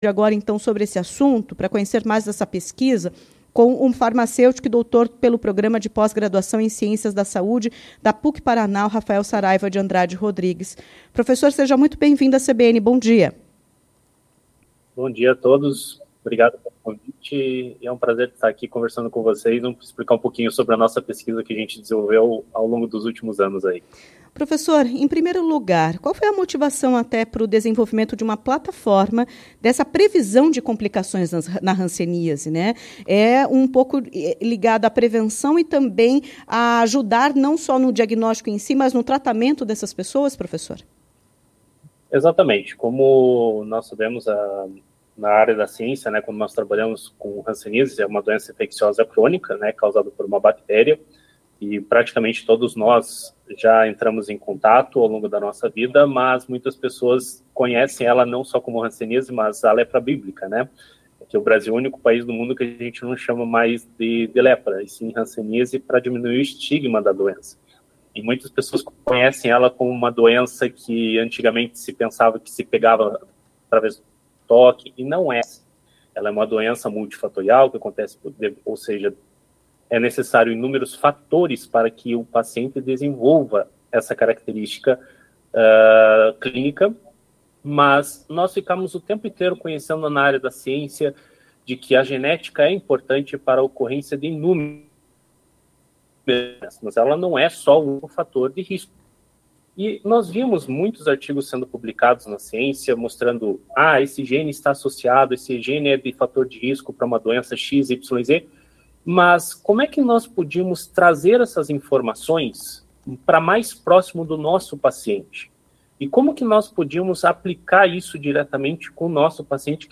contou mais detalhes da pesquisa em entrevista à CBN Curitiba nesta quarta-feira